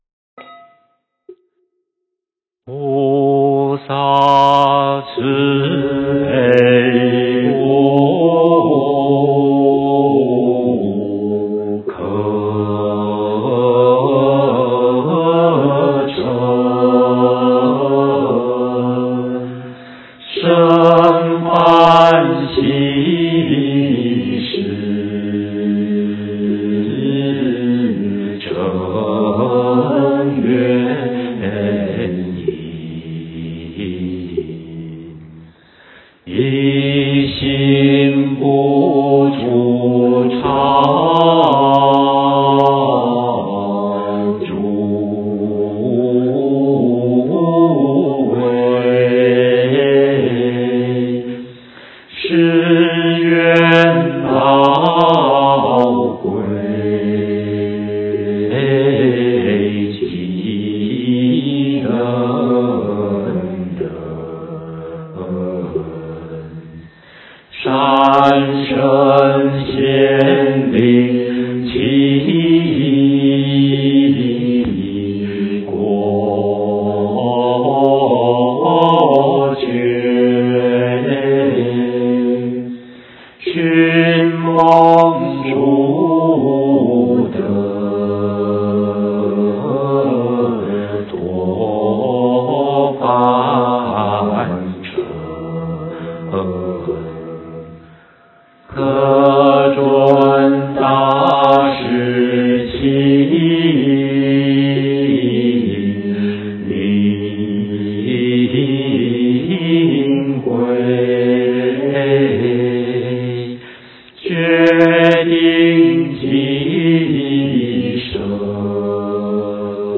梵呗教学音档
《自宅安座法会》梵呗教学音档